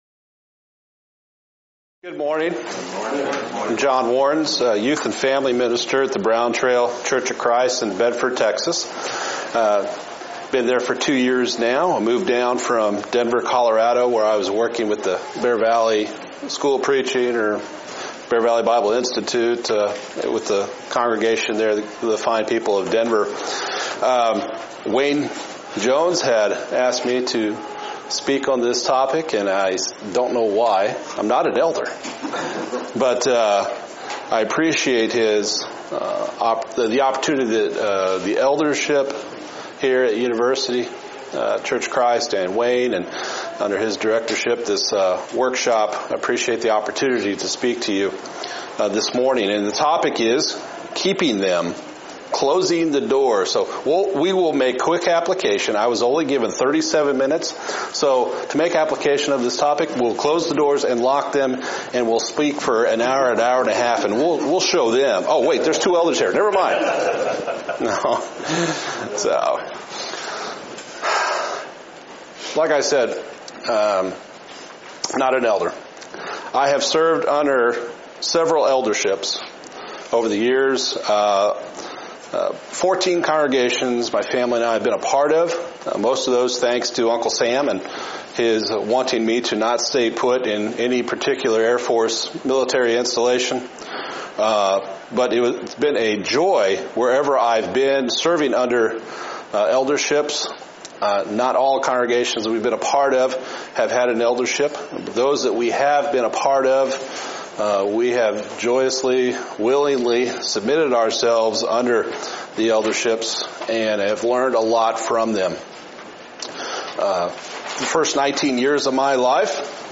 Event: 2018 Focal Point
Preacher's Workshop
lecture